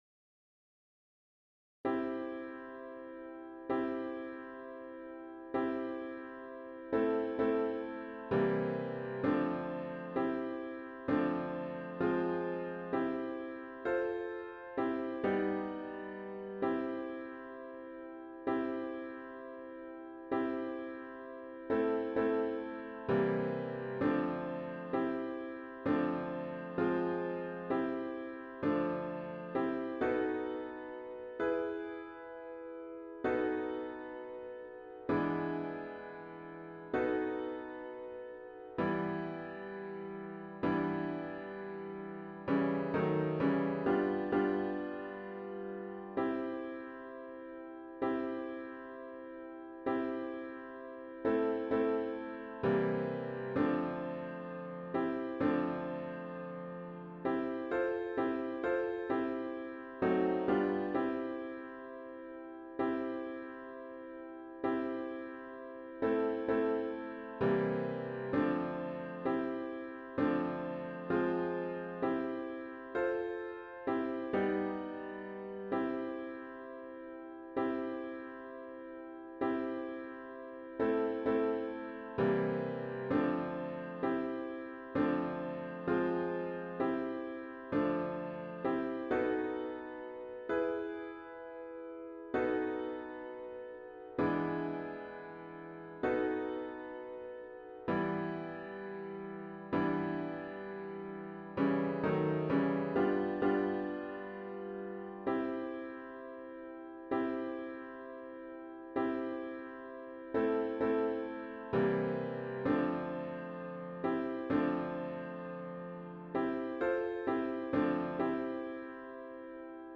An audio of the chords only version is